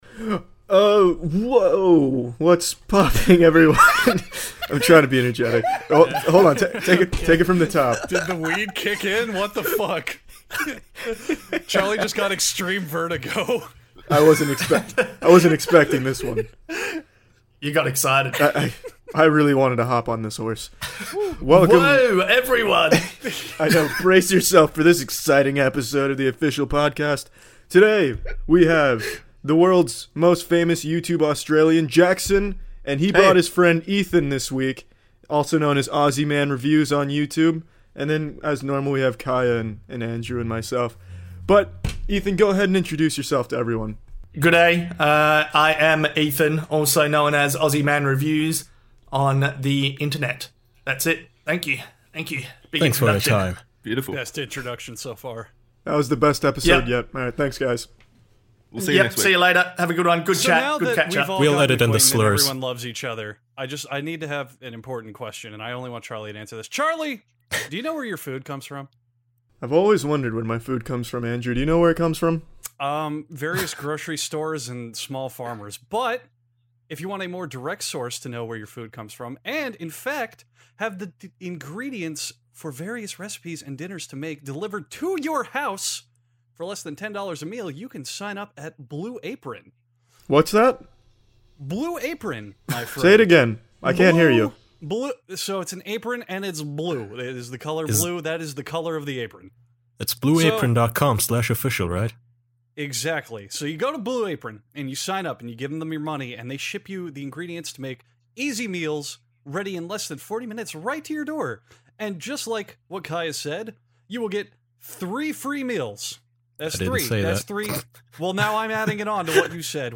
Five close friends gather around to discuss Australia.